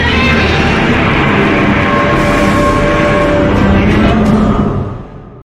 File:Super Godzilla Godziban Roar.mp3
Super_Godzilla_Godziban_Roar.mp3